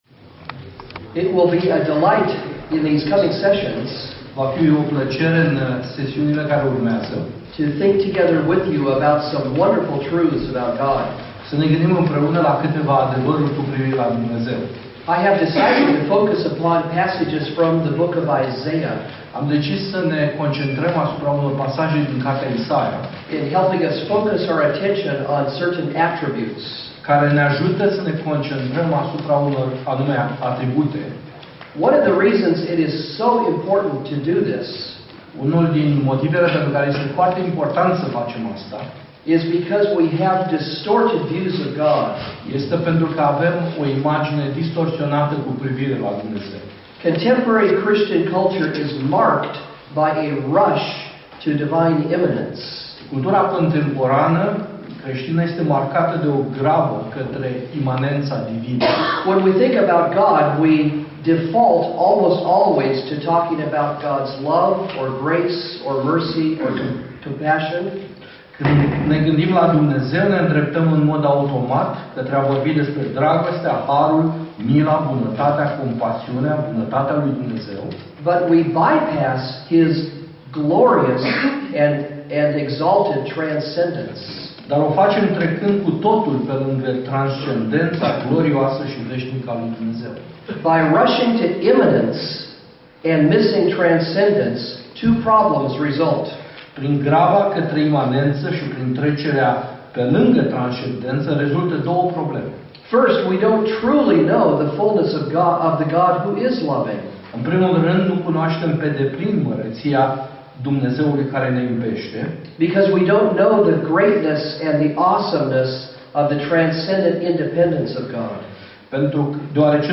Predici Complete